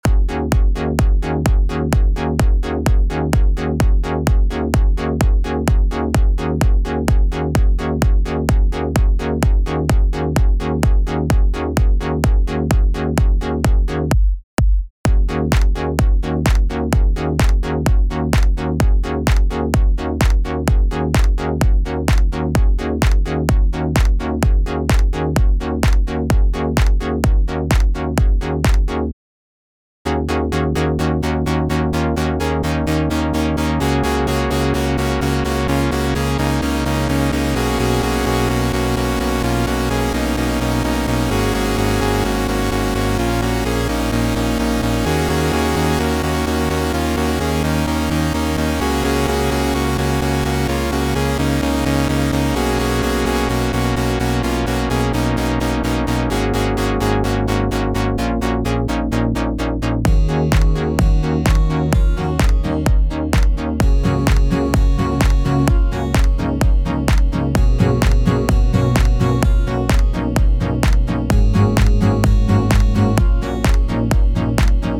more wips from me - this is kinda just a skeleton of a track right now, but it sounds kinda cool to me
house music
electronic dance
this is so dreamy
it was just my attempt at recreating a moog sound in Serum.